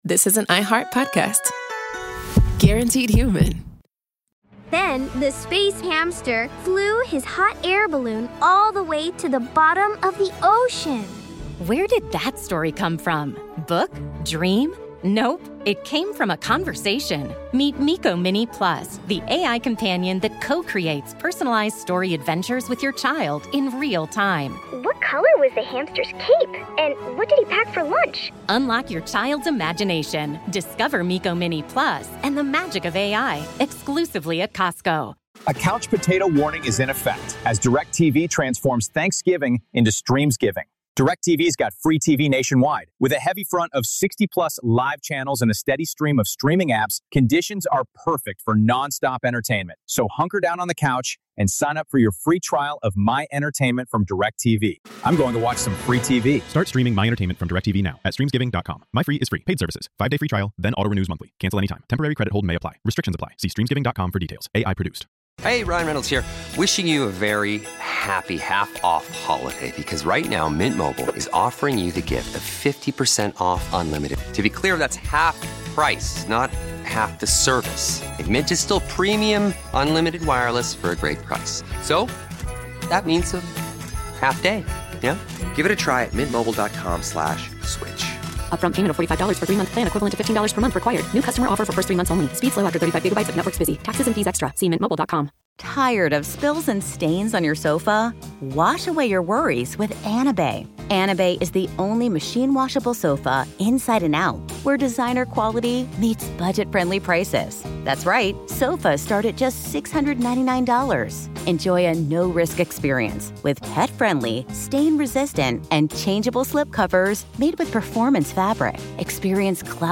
Watergate brought that world crashing down, sending him into a federal prison and into a reckoning he never expected. In this final interview before his passing, Colson looks back on the scandal, the time behind bars, and the shift in faith that shaped the rest of his life.